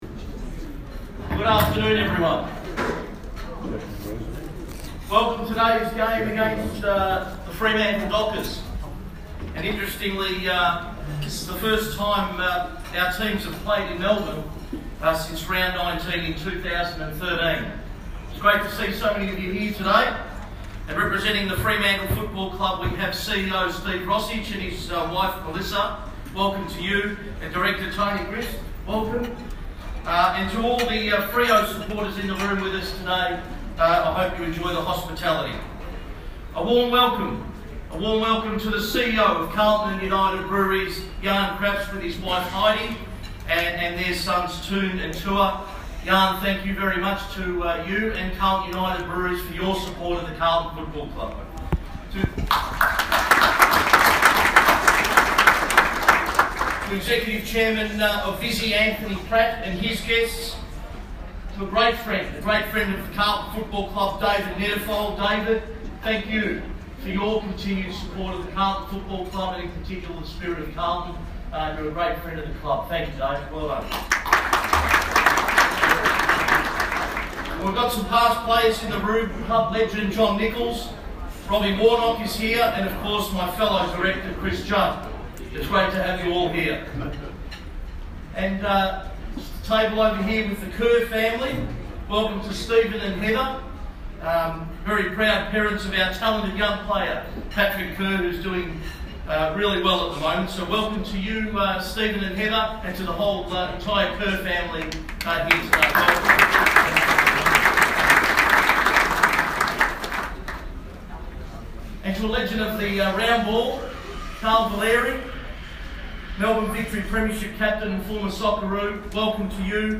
President's speech | Round 13